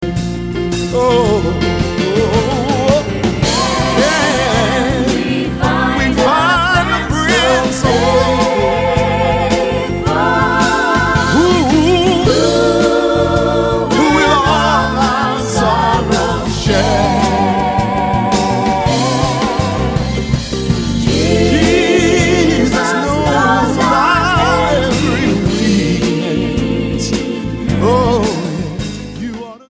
STYLE: Gospel
so immaculate production